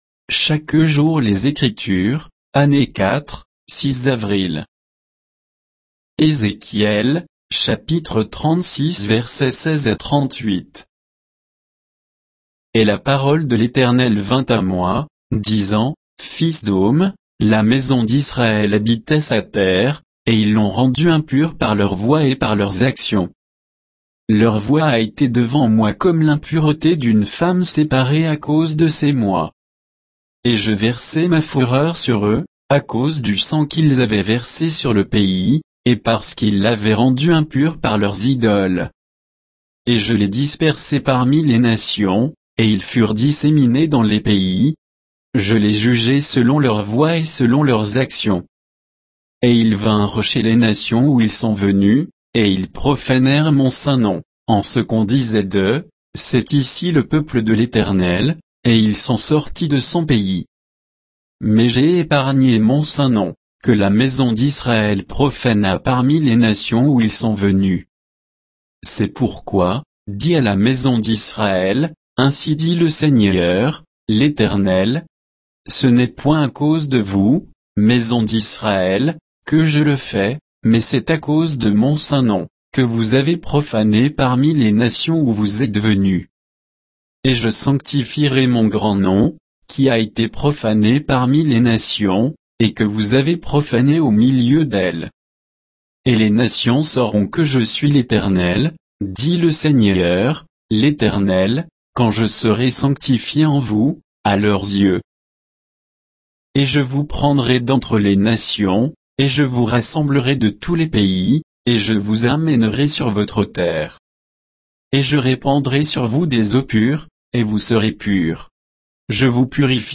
Méditation quoditienne de Chaque jour les Écritures sur Ézéchiel 36